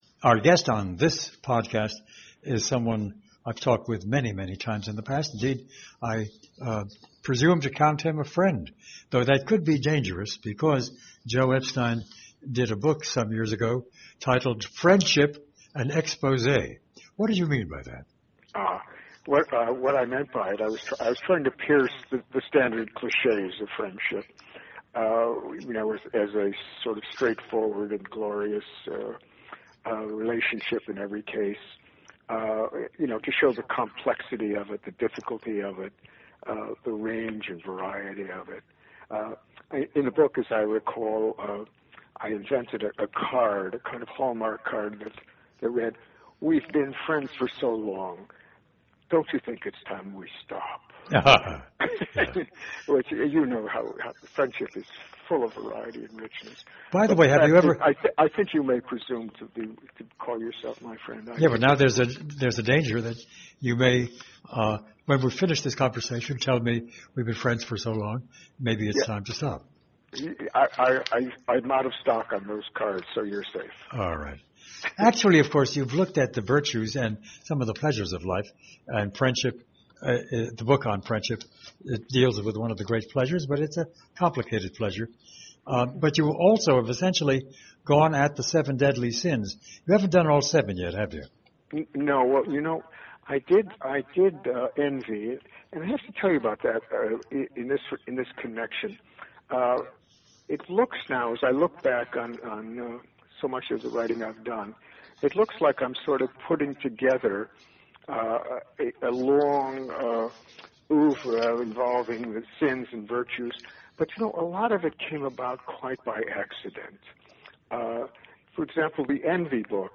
A Conversation With Joseph Epstein
Joseph Epstein sees through all of our foibles, affectations, eccentricities and higher-level stupidities including his own (though he is very light on the last!). Here he is in a rambling 2013 conversation which, like all his contributions to this most basic human art form, delights, illuminates and provokes.